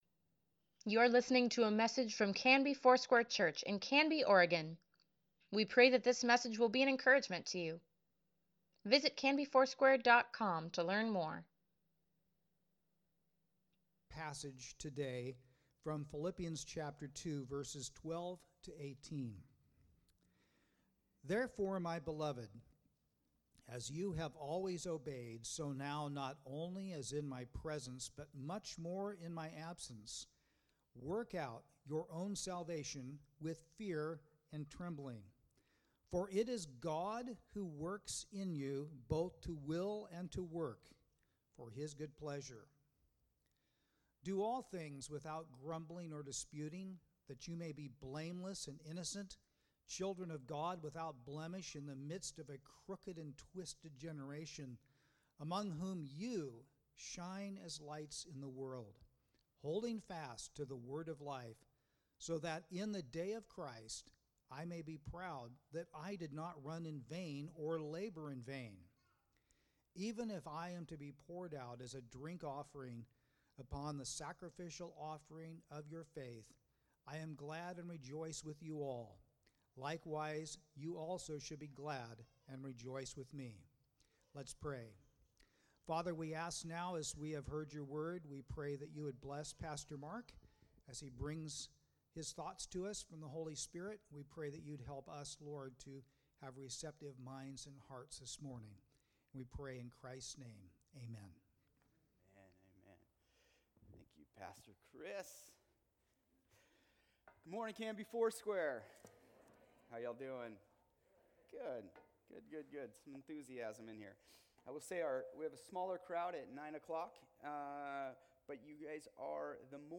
Weekly Email Water Baptism Prayer Events Sermons Give Care for Carus CONFIDENT: Philippians 2:12-18 November 7, 2021